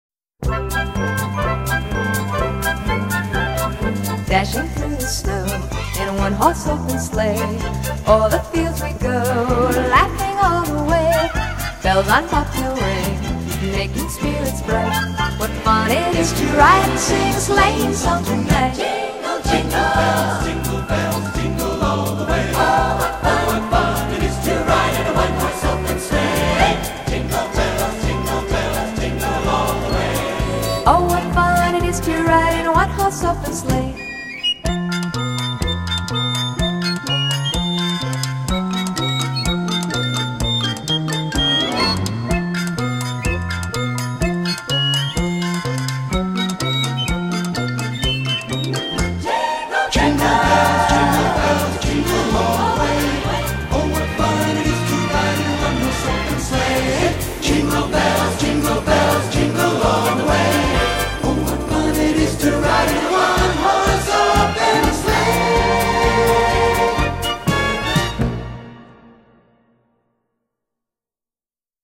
音乐类型： Pop, Christmas, PIANO　　　　 .